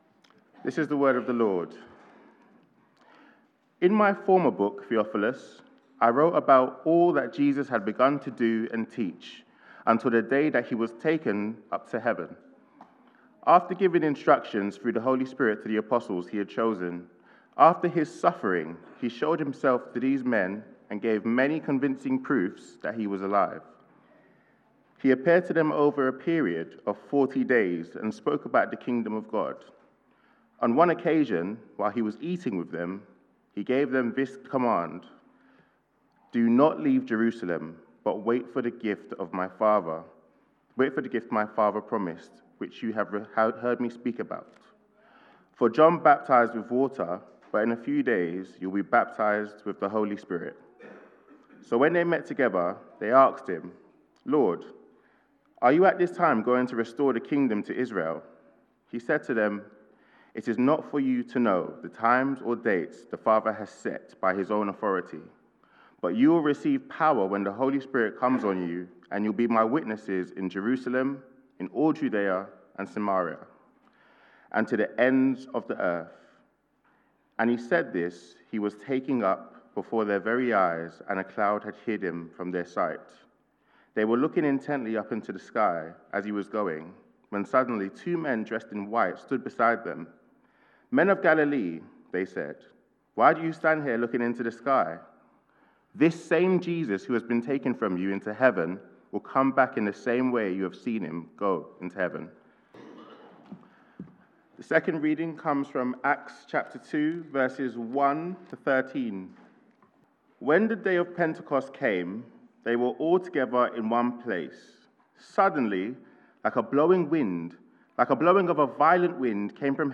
Sunday Service
Theme: All Age Invitational Service - Come Holy Spirit Sermon